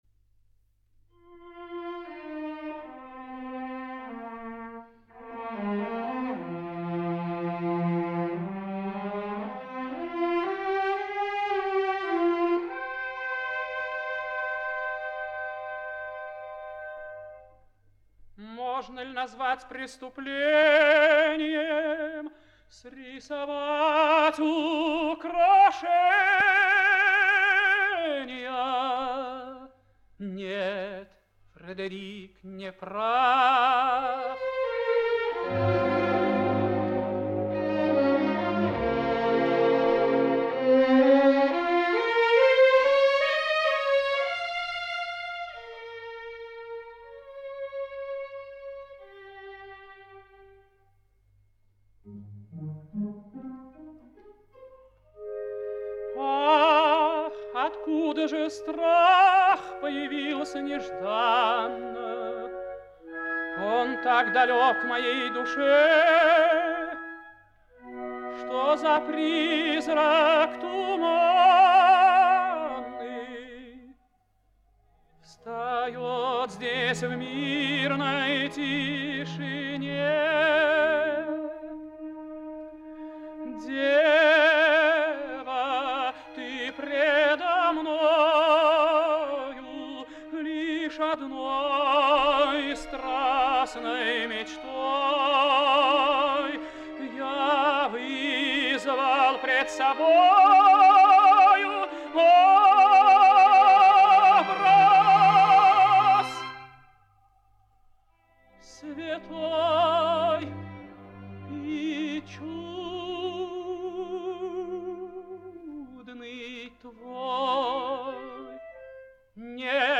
лирический тенор